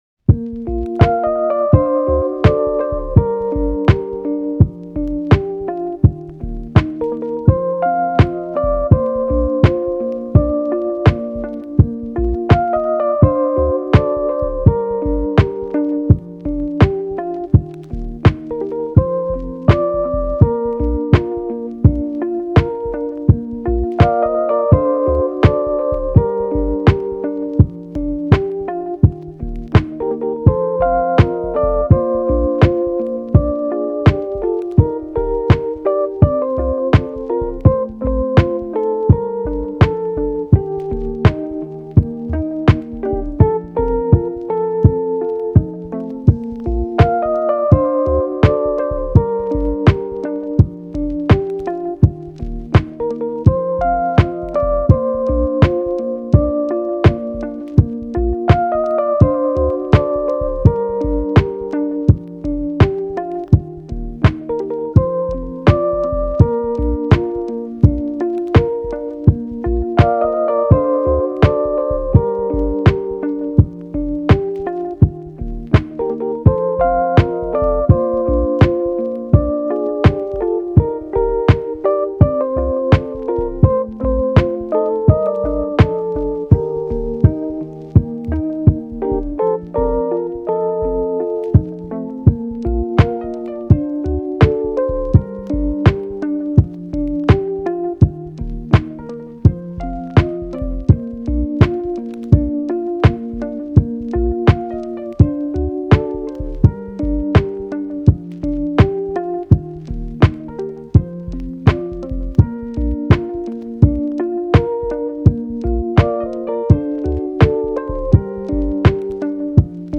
ループ版有り